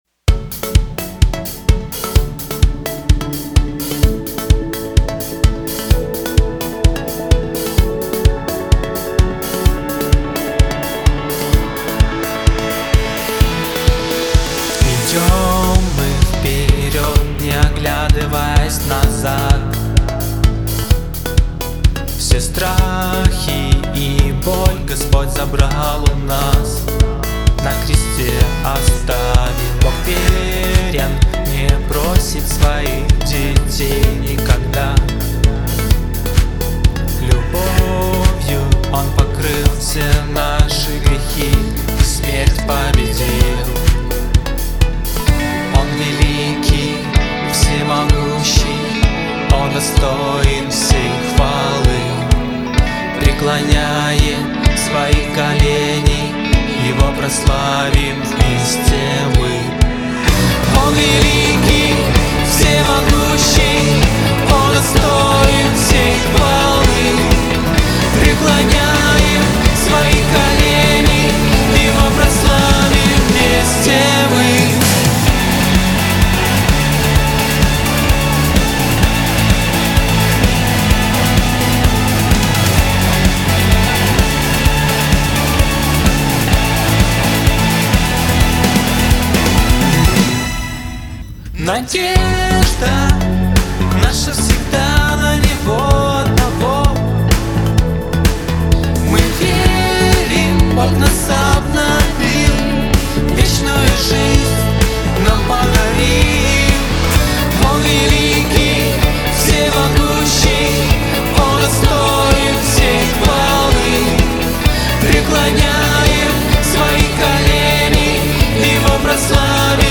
песня
596 просмотров 904 прослушивания 113 скачиваний BPM: 128